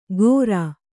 ♪ gōrā